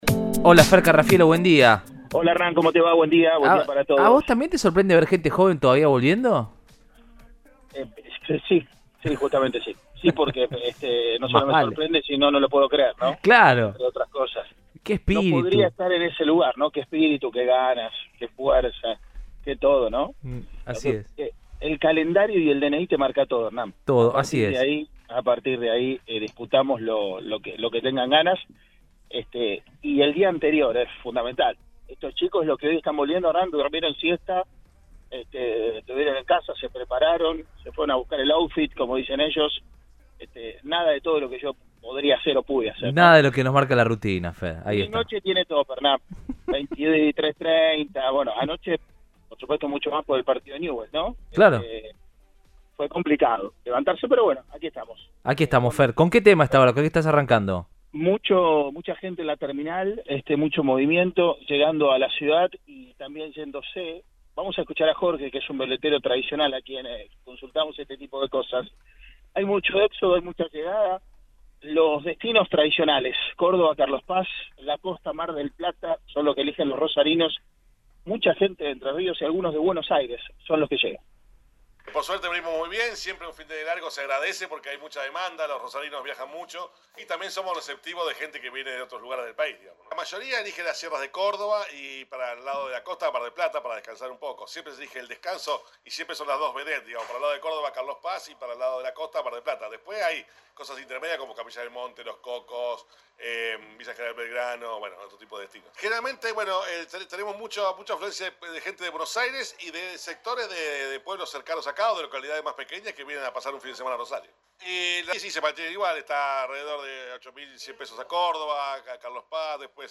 dialogó con el móvil de Cadena 3 Rosario, en Radioinforme 3